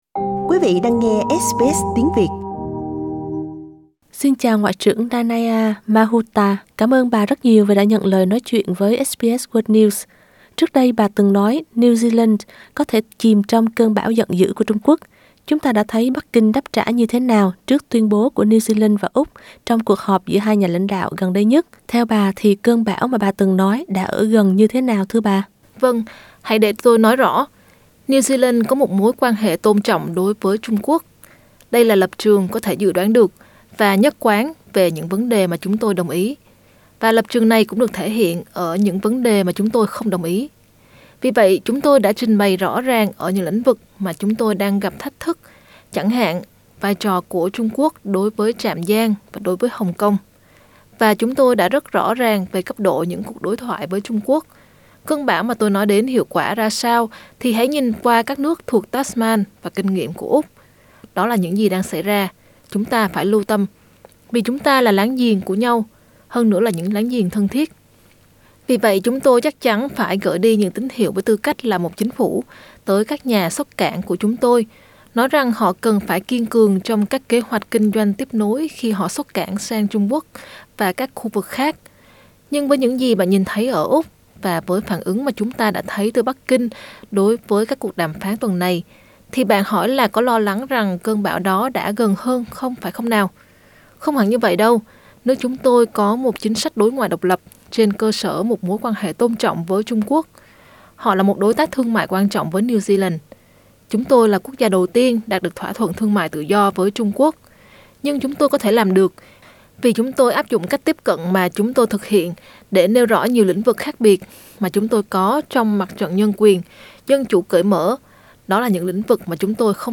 New Zealand's Foreign Minister, Nanaia Mahuta, sat down to speak with SBS News in Wellington.